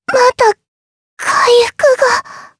Lewsia_A-Vox_Dead_jp.wav